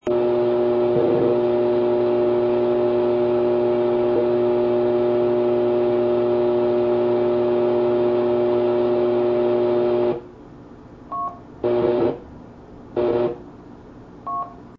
[App_rpt-users] Random Keyup & Hum
However also considered someone jamming / txing a near dead carrier with a
hum on their signal.
has the courtesy tone, which is the local tone, as if it was a station here
Name: Noise3d.mp3